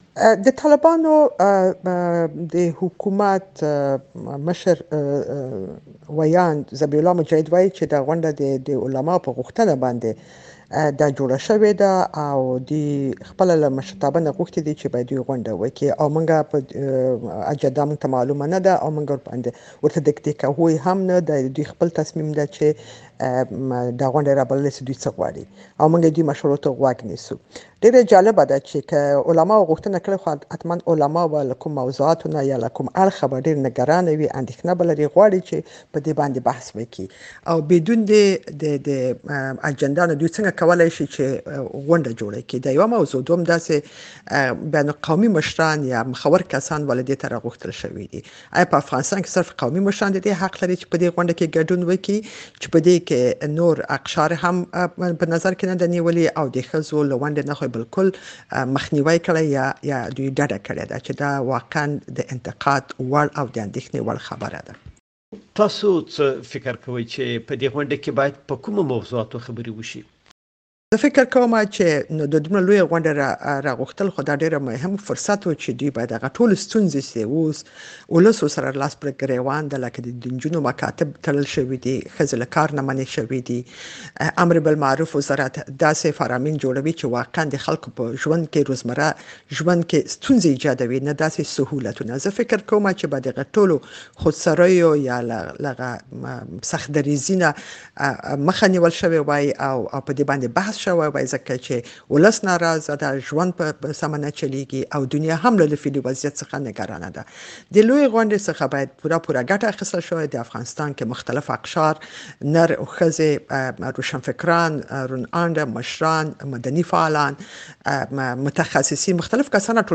د شينکۍ کړوخېل مرکه